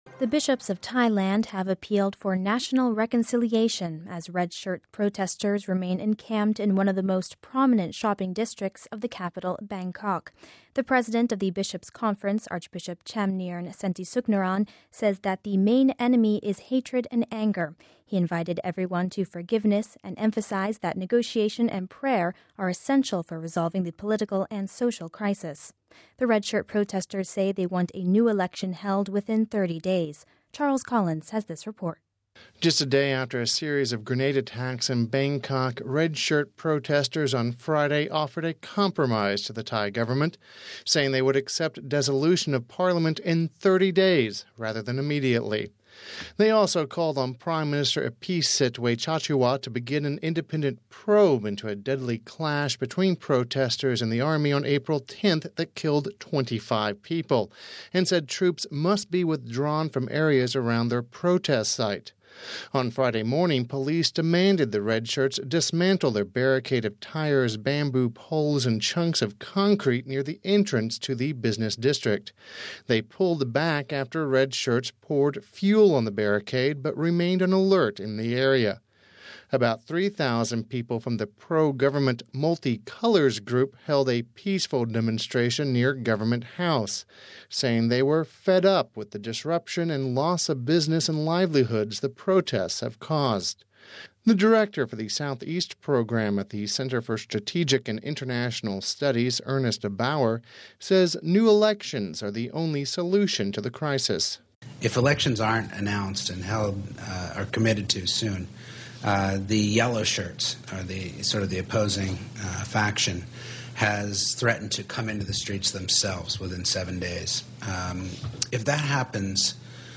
We have this report: RealAudio